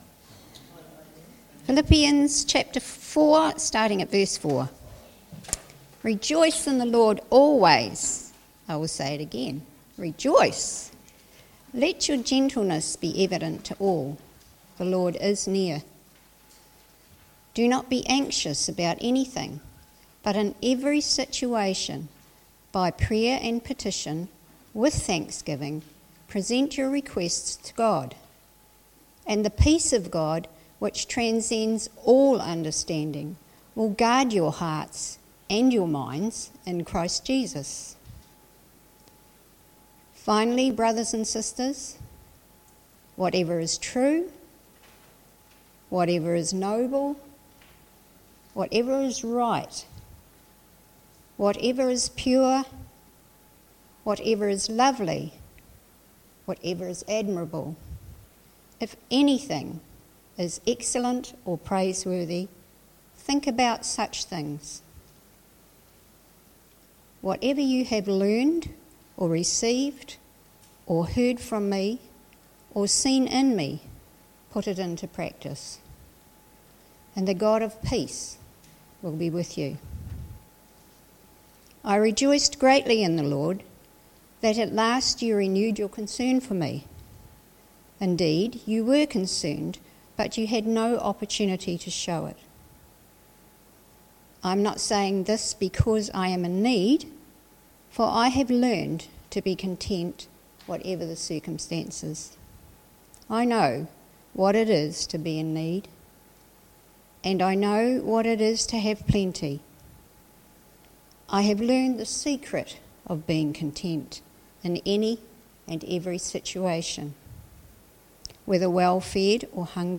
Celebration Preacher